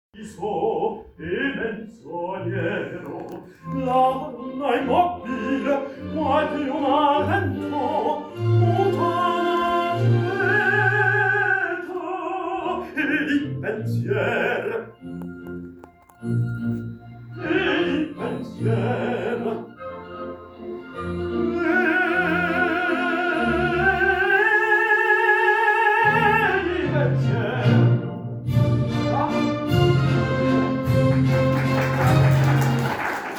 concert conférence